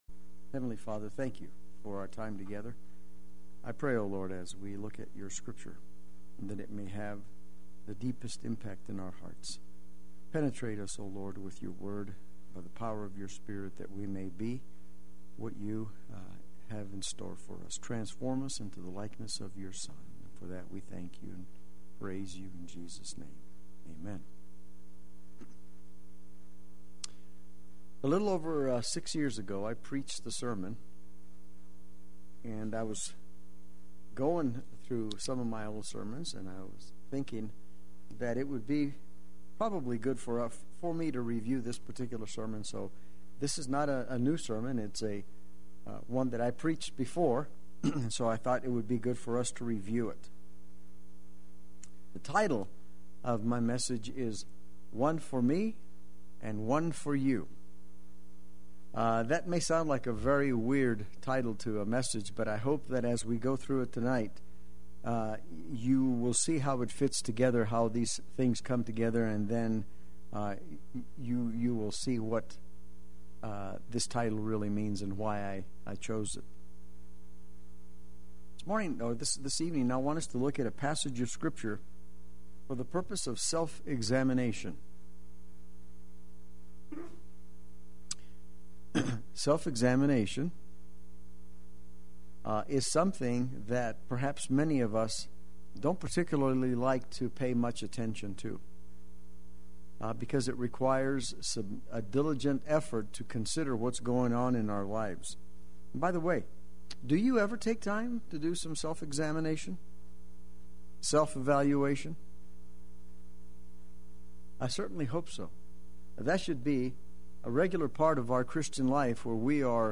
Play Sermon Get HCF Teaching Automatically.
One For Me and One For You Wednesday Worship